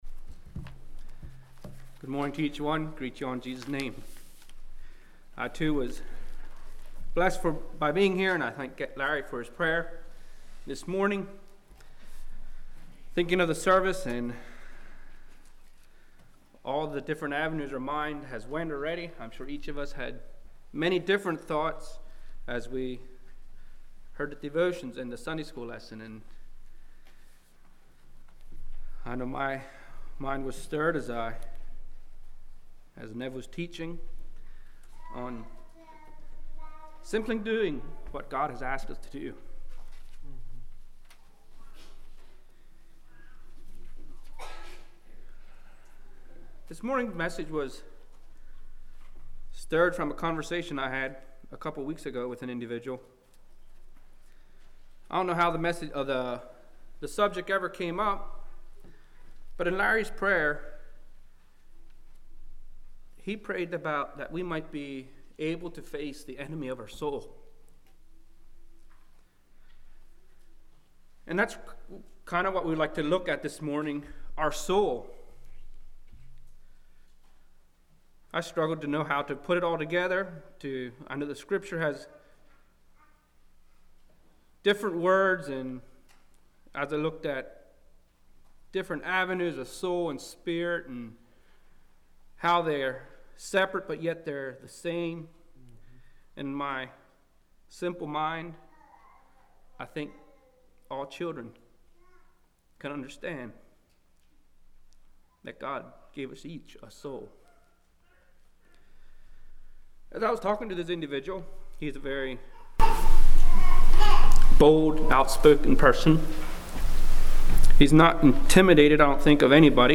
2020 Sermon ID